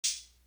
NB Hat.wav